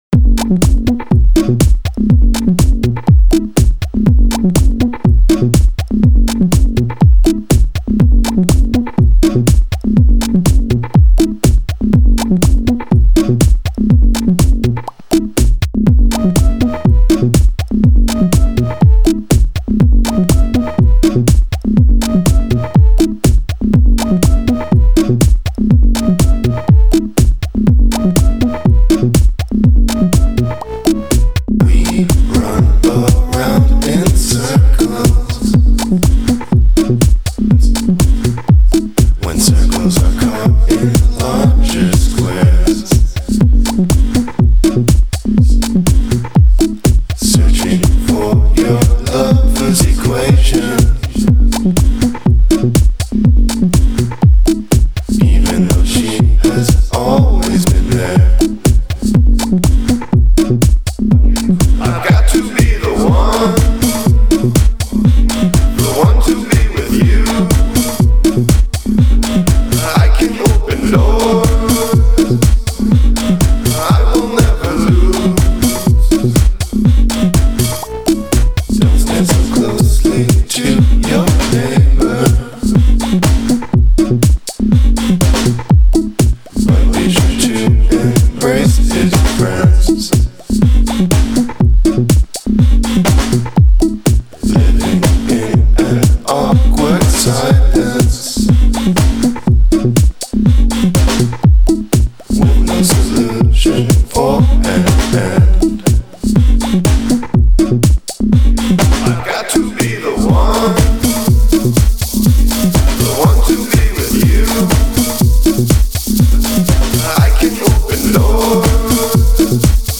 Funky mid tempo dance track.
micro-house
I love the dead pan vocals on this track.